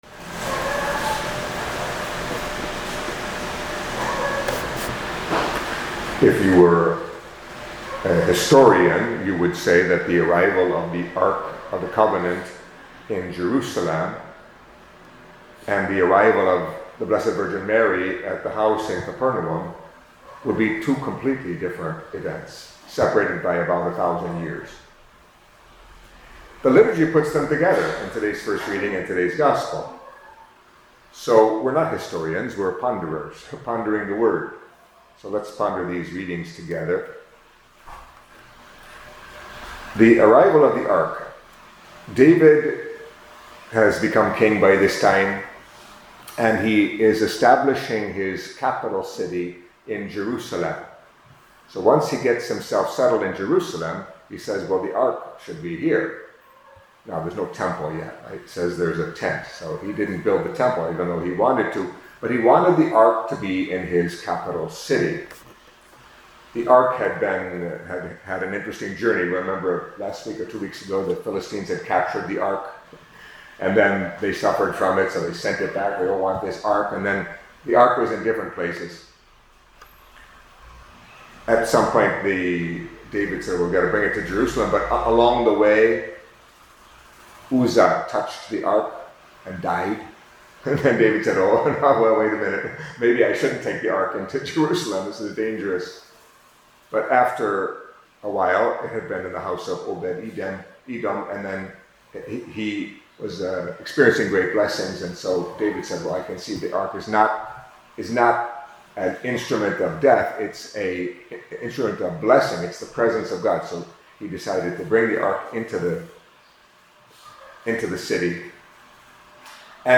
Catholic Mass homily for Tuesday of the Third Week in Ordinary Time